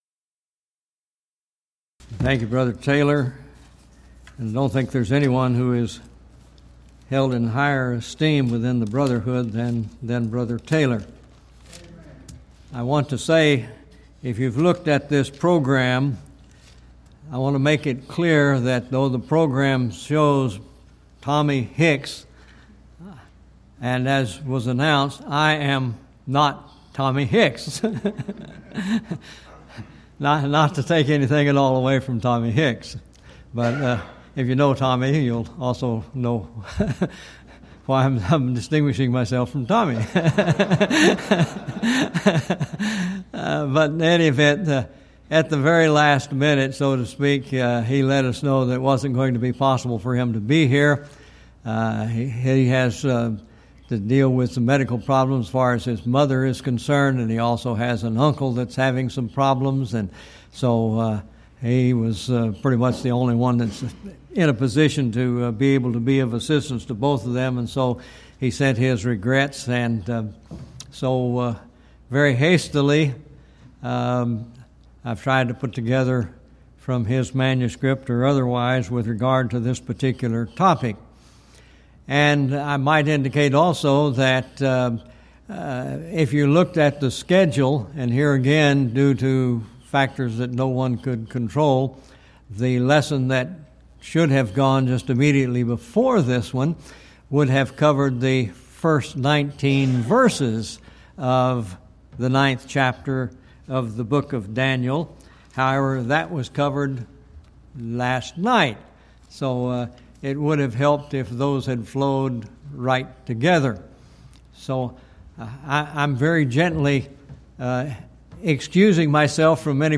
Event: 11th Annual Schertz Lectures
lecture